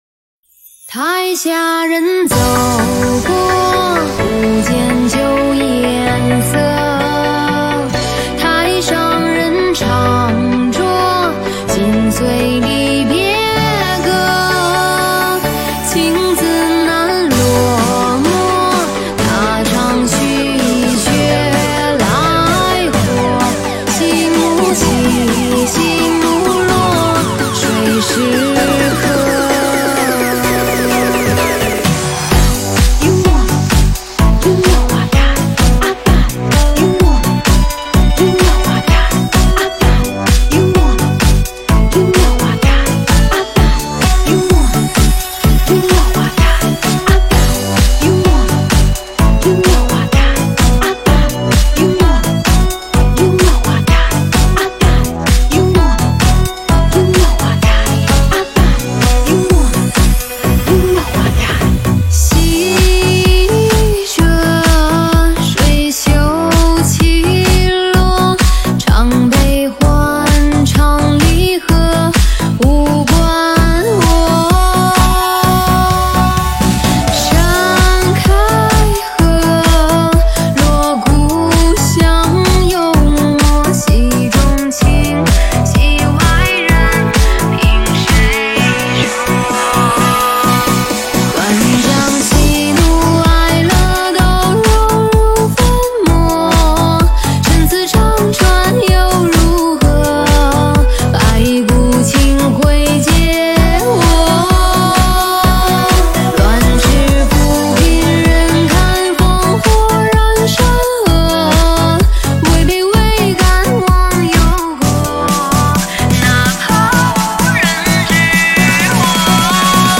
本铃声大小为1360.6KB，总时长156秒，属于DJ分类。